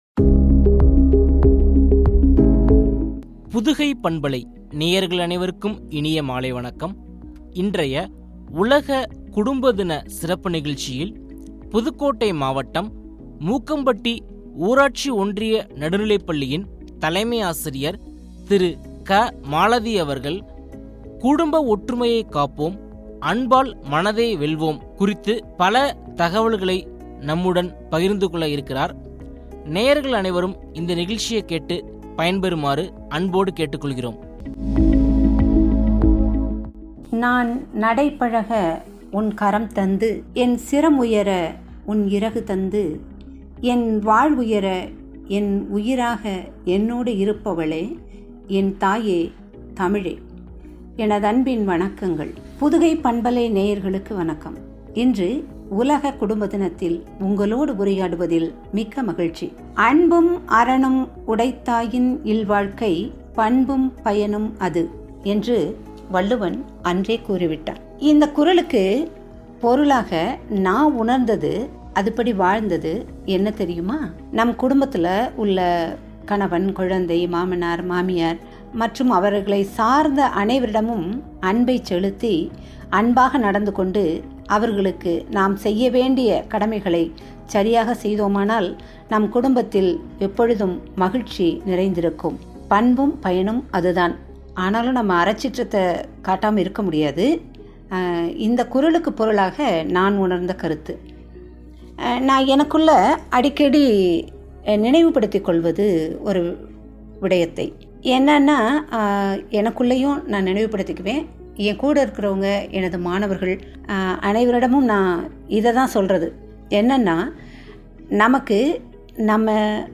என்ற தலைப்பில் வழங்கிய உரையாடல்.